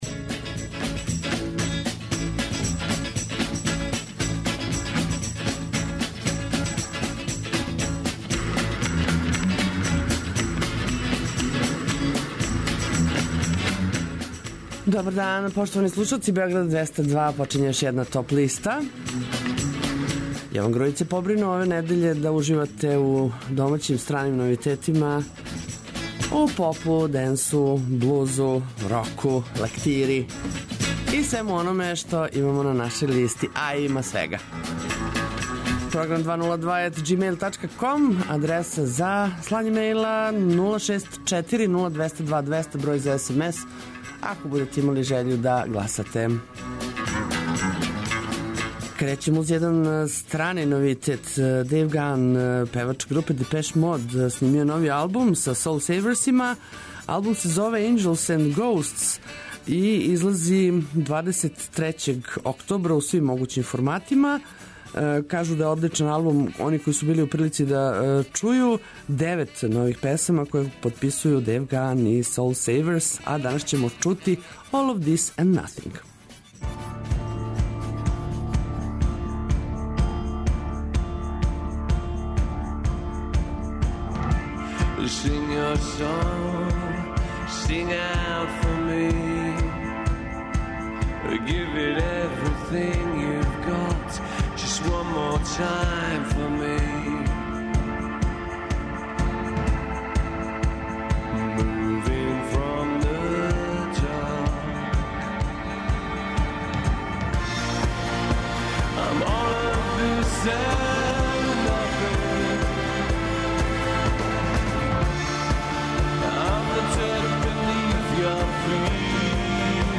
Најавићемо актуелне концерте у овом месецу, подсетићемо се шта се битно десило у историји музике у периоду од 12. до 16. октобра. Ту су и неизбежне подлисте лектире, обрада, домаћег и страног рока, филмске и инструменталне музике, попа, етно музике, блуза и џеза, као и класичне музике.